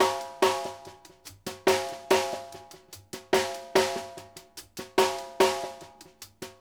Index of /90_sSampleCDs/Sampleheads - New York City Drumworks VOL-1/Partition F/SP REGGAE 72